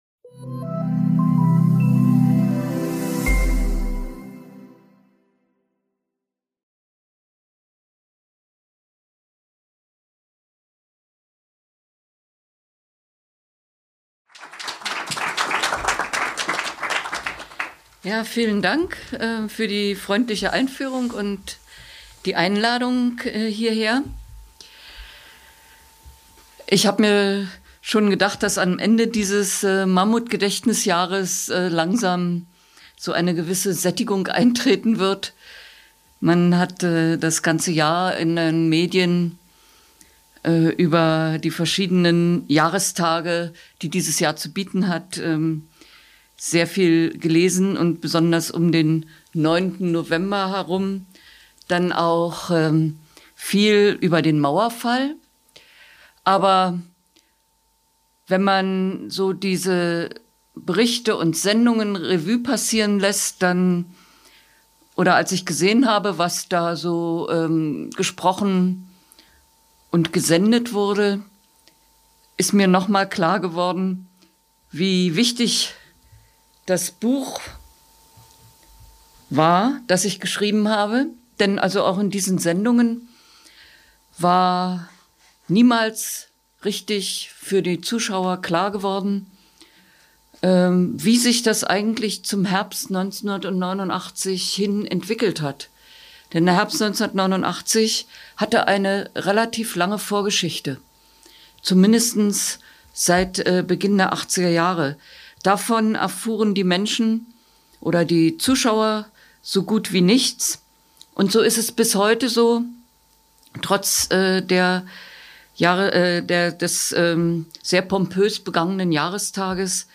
Basierend auf Tagebuchnotizen sowie Meldungen der Presse, schilderte mit Vera Lengsfeld am 11. Dezember 2014 eine führende Vertreterin der ehemaligen DDR-Bürgerrechtsbewegung in der Bibliothek des Konservatismus den Prozeß der friedlichen Revolution.